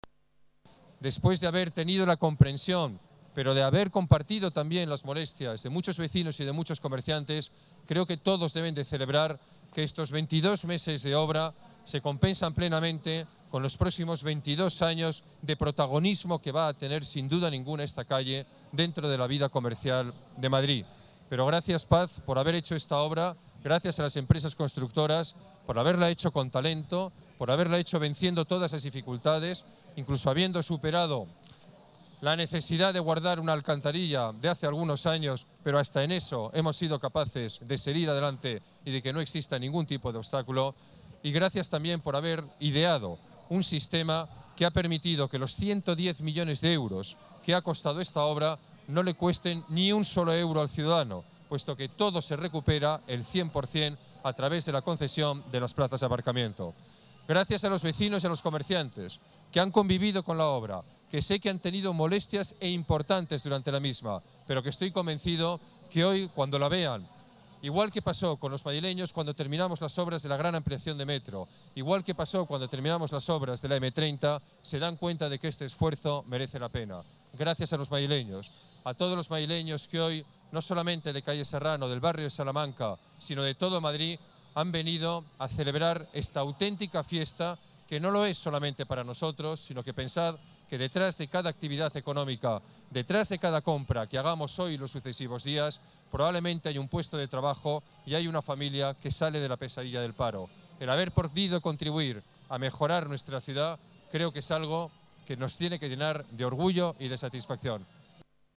Nueva ventana:Declaraciones Alcalde sobre inversión en Serrano